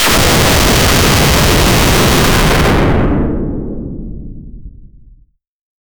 Free AI Sound Effect Generator
Create an Loud Explosion with a After effect Laser and blud
create-an-loud-explosion--eofyrgvd.wav